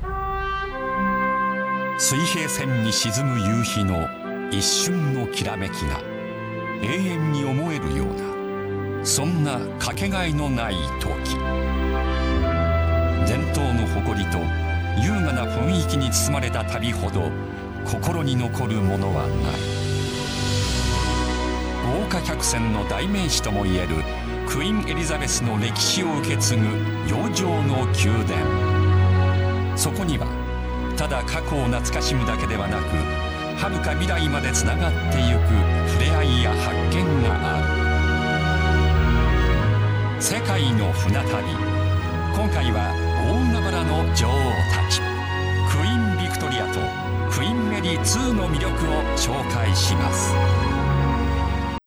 オフィス・ふくし：ナレーションリスト 番組